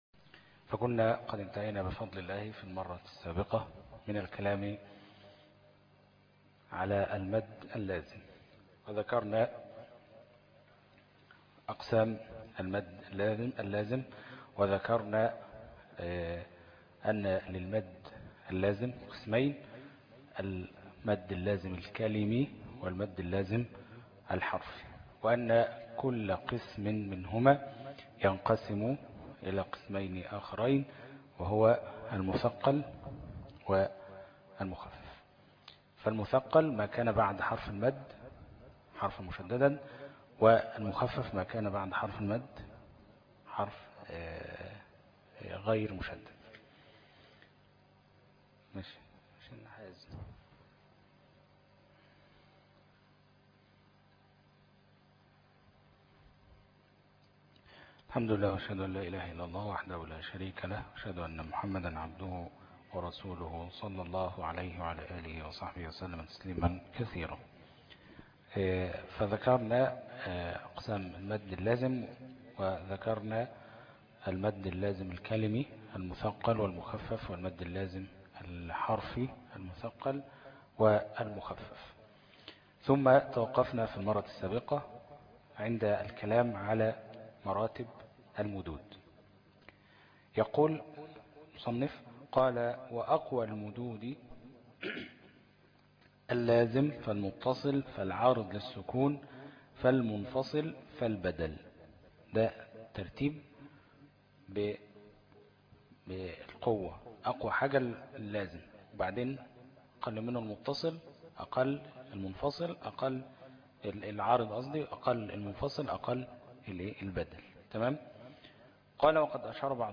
دروس التجويد 16 - الفرقة التمهيدية - الشيخ أبو إسحاق الحويني